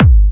Kicks
pcp_kick14.wav